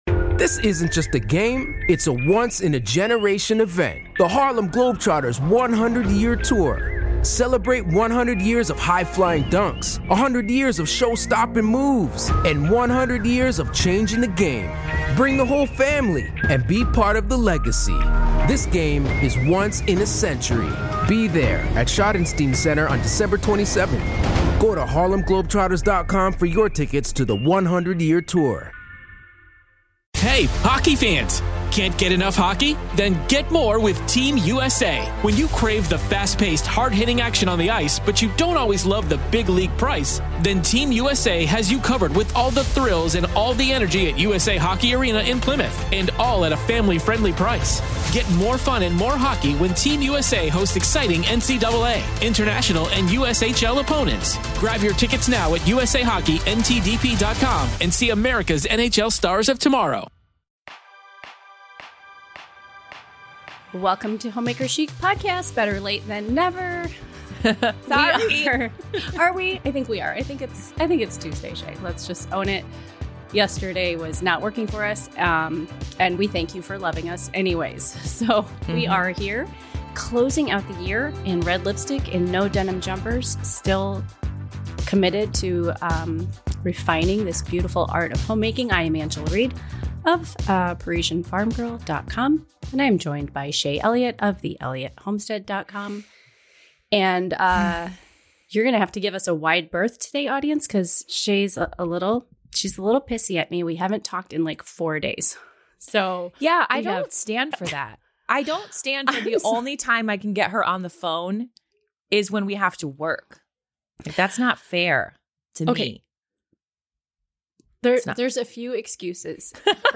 Hopes, dreams, goals, resolutions... fun conversation today as we prep for New Year's Eve!